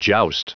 Prononciation du mot joust en anglais (fichier audio)
Prononciation du mot : joust